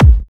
Kick h.wav